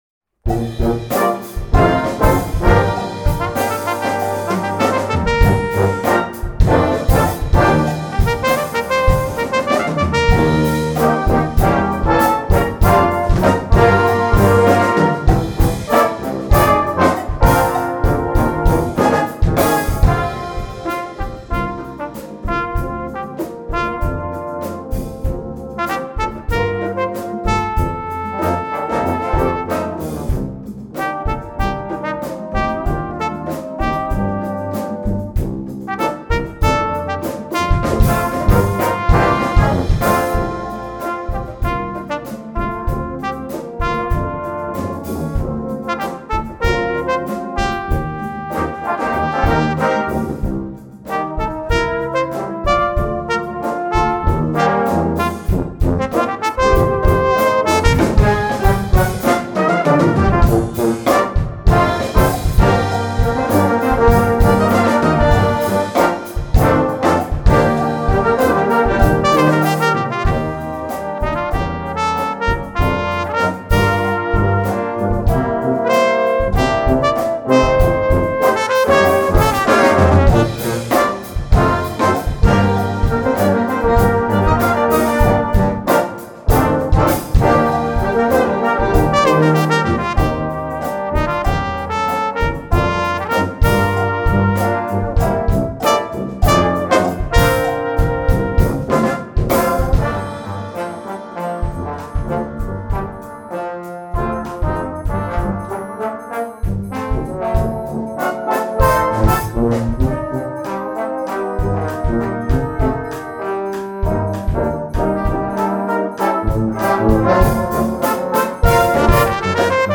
Composition Style: Cornet Solo
Set in a modern and uplifting funk rock style
cornet